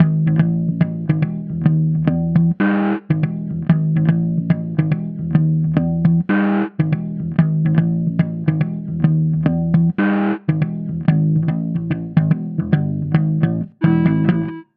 描述：一个带有失真触感的切分节奏的4/4摇摆掌。
Tag: 130 bpm Fusion Loops Bass Guitar Loops 2.48 MB wav Key : Unknown